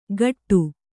♪ gaṭṭu